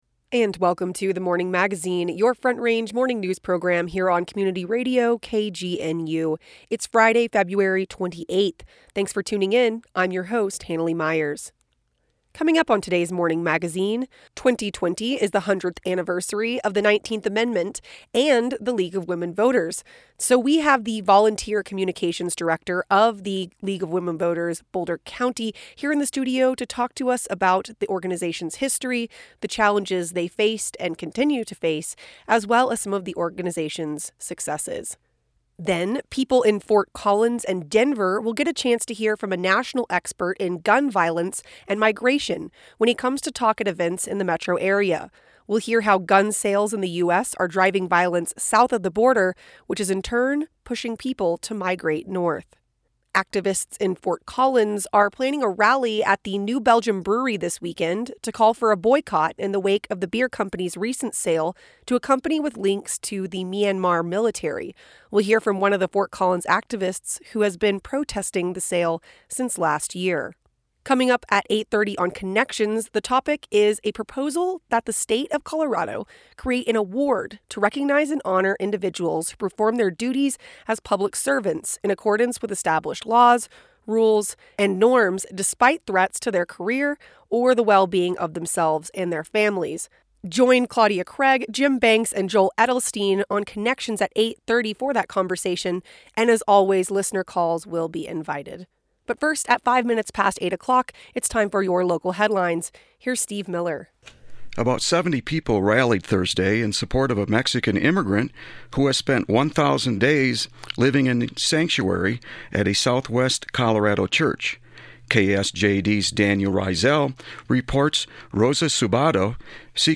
We speak with the League of Women Voters of Boulder County on the 100th anniversary of the national organization and the 19th Amendment. Then, a report of the connection between gun sales south of the border and migration followed by an interview with one of the activists protesting the sale of New Belgium Brewery to a company with ties to the Myanmar military.